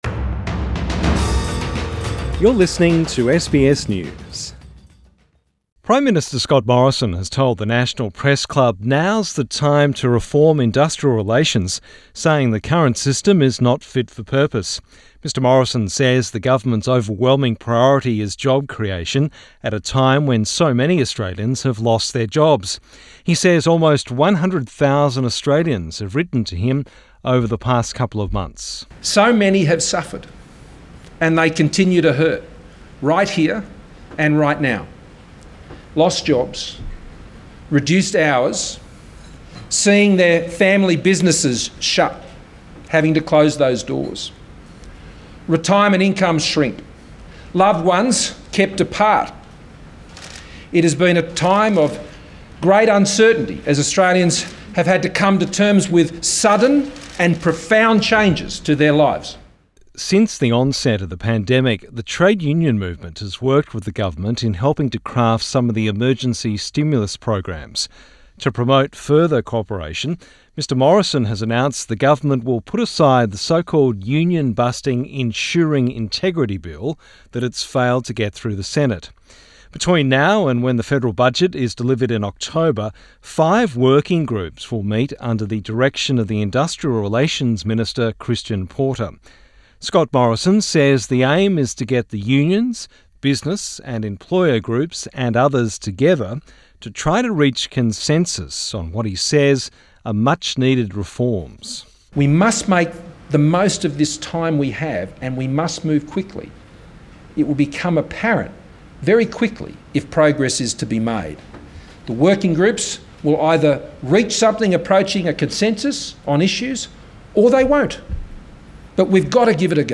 Prime Minister Scott Morrison at the National Press Club in Canberra Source: AAP